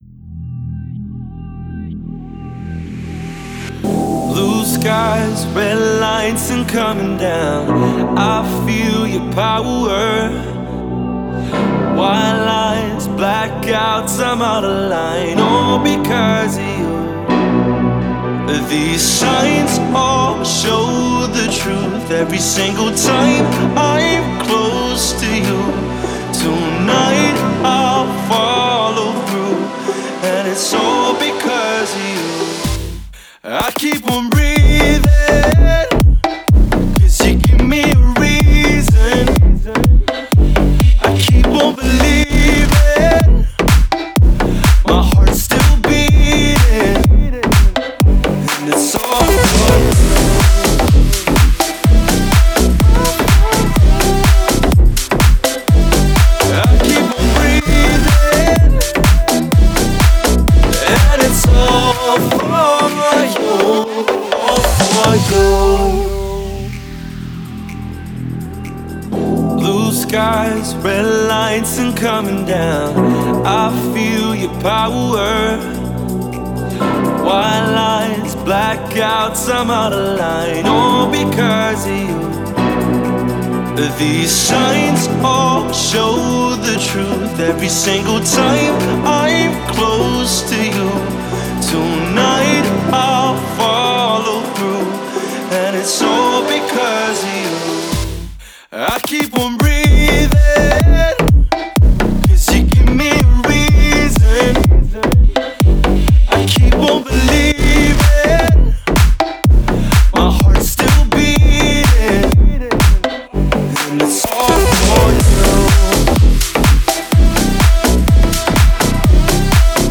Настроение композиции – оптимистичное и вдохновляющее.